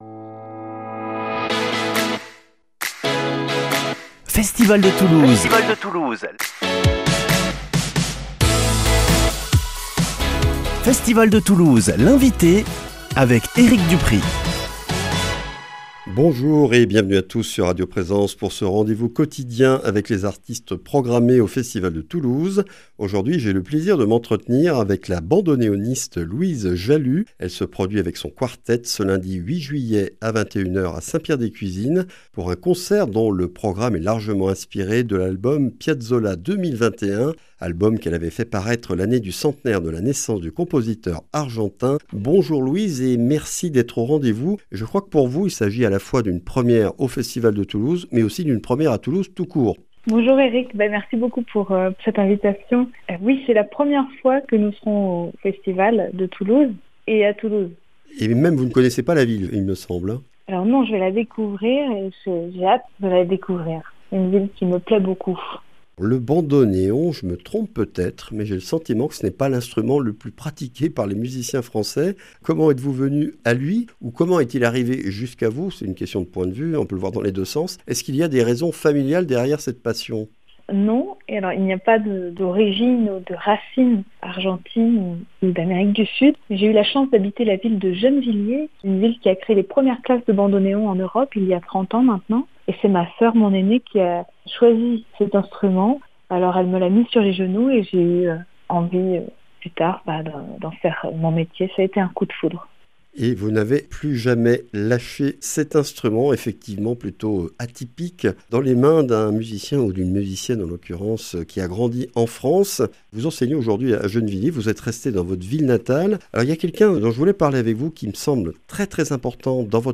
Festival de Toulouse - Interviews